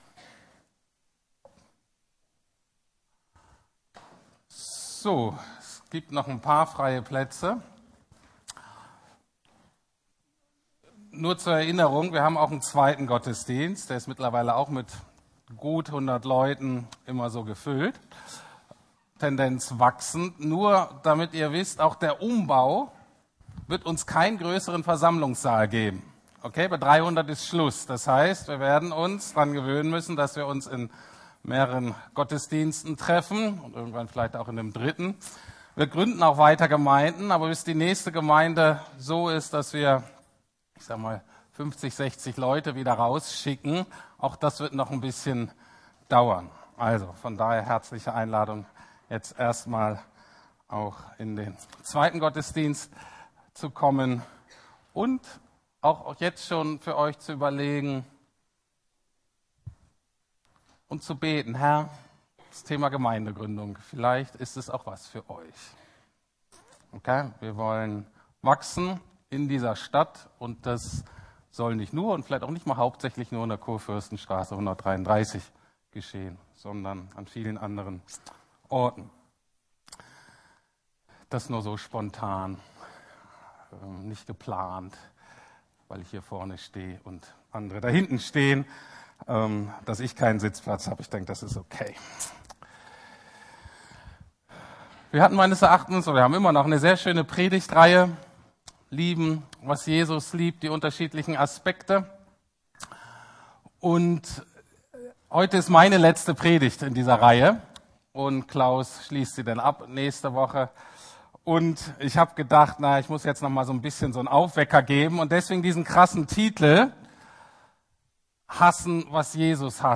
Hassen, was Jesus hasst - Götzen ~ Predigten der LUKAS GEMEINDE Podcast